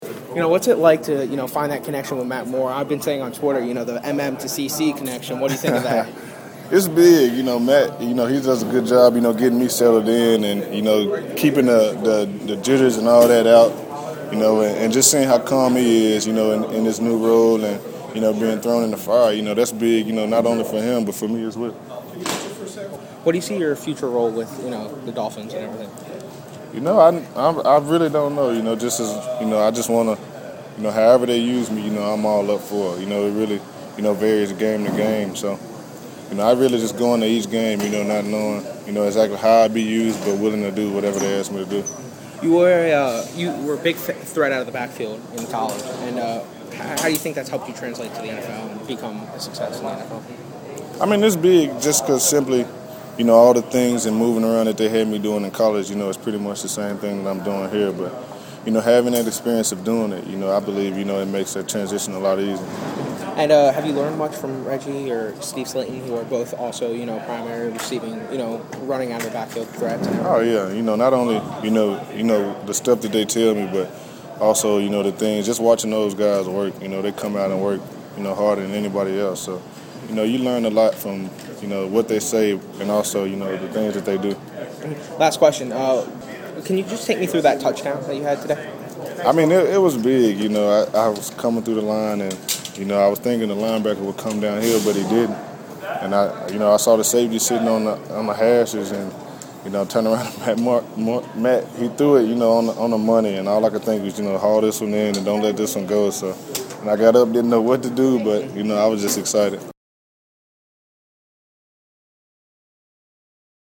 Walking back to Dolphins Locker Room, I saw a familiar face in the interview room, Charles Clay.
phins-charles-clay-locker-room.mp3